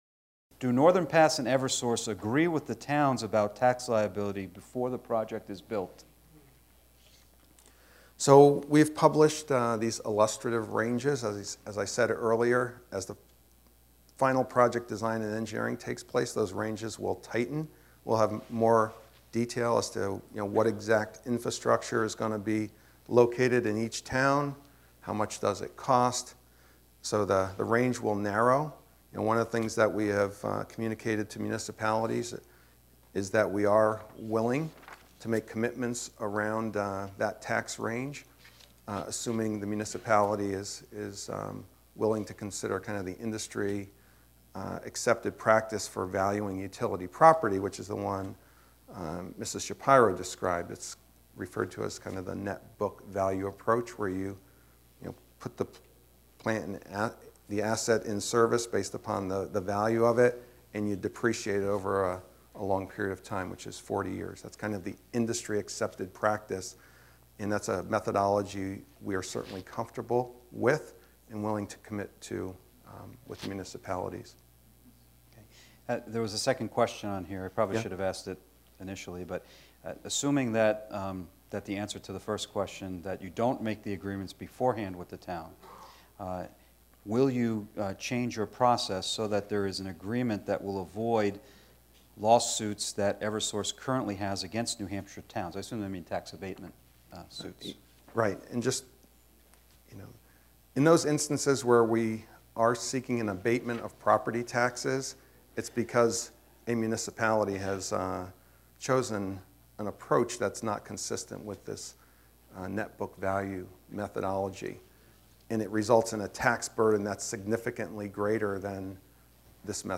QUESTIONS & ANSWERS
QandAsession4.mp3